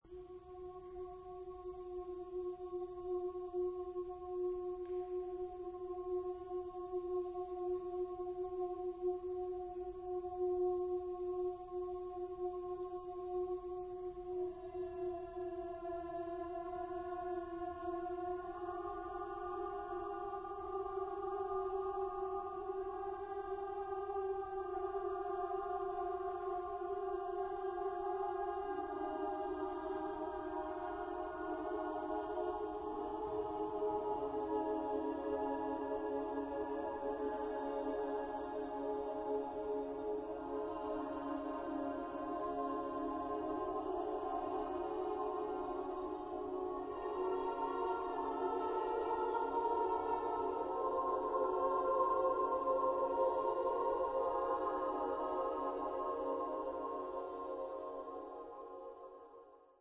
Género/Estilo/Forma: Sagrado ; contemporáneo
Tipo de formación coral: 4S-4A-4T-4B  (16 voces Coro mixto )